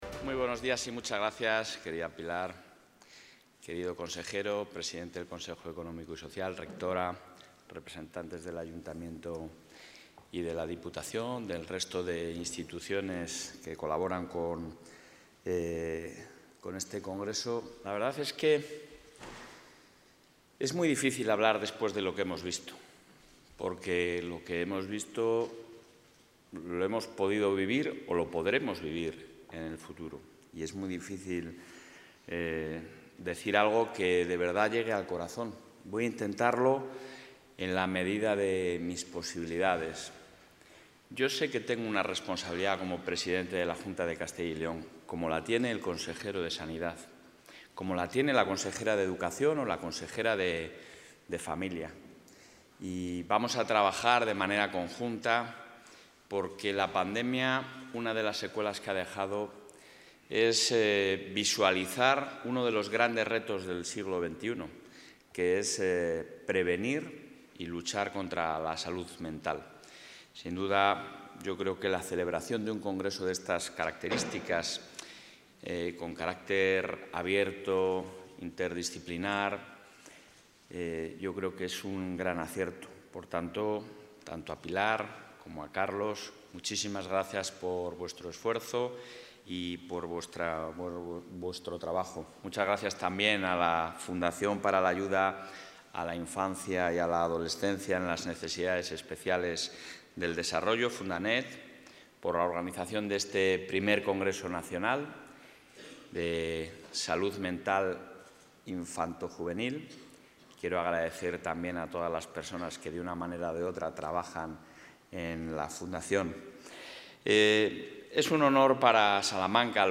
Intervención del presidente de la Junta.
El presidente de la Junta de Castilla y León, Alfonso Fernández Mañueco, ha inaugurado el I Congreso Nacional de Salud Mental Infanto-Juvenil, organizado por la Fundación para la Ayuda a la Infancia y Adolescencia en las Necesidades Especiales del Desarrollo (FUNDANEED) en Salamanca.